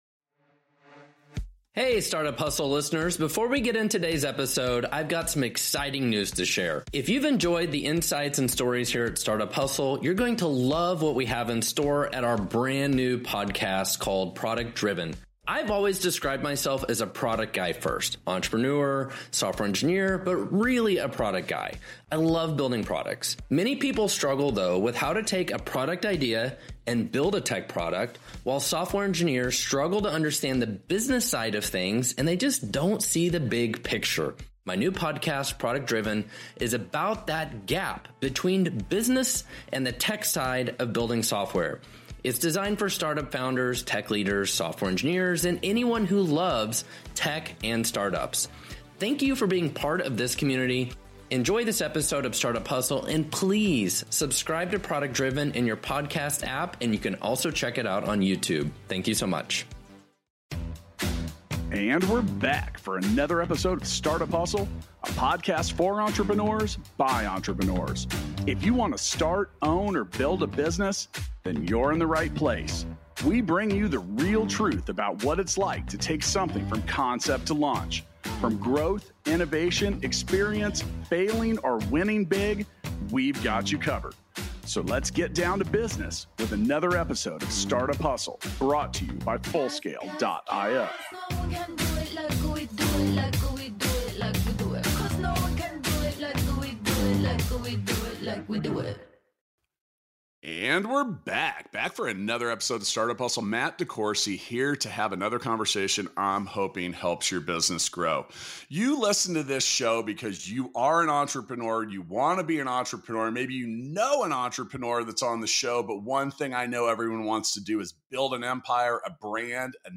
for an engaging discussion on constructing your empire through brand, network, and income.